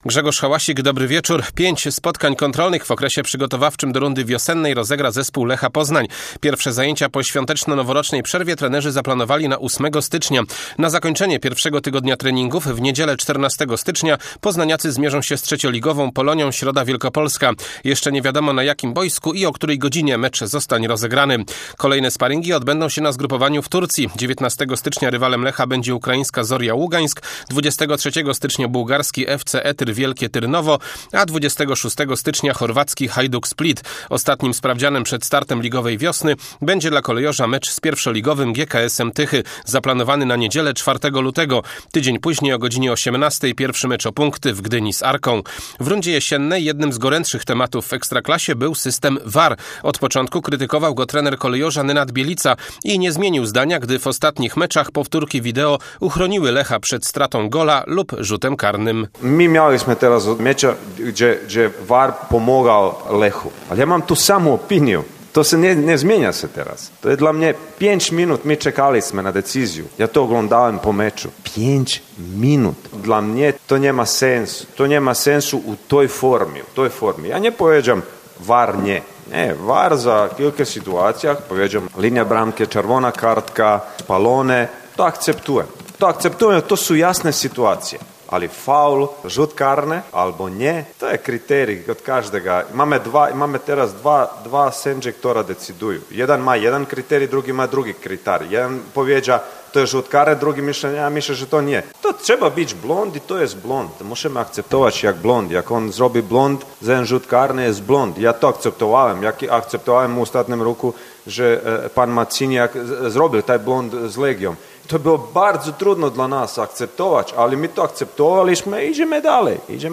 27.12 serwis sportowy godz. 19:05